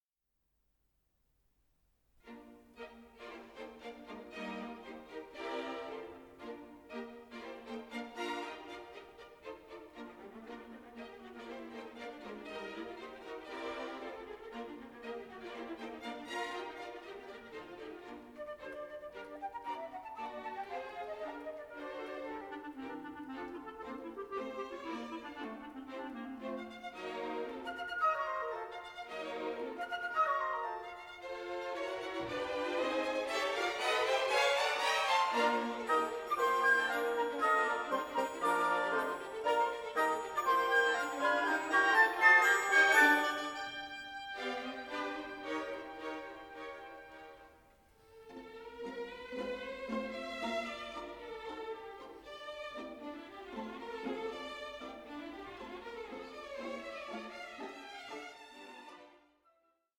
(DSD DSF) Stereo & Surround  20,99 Select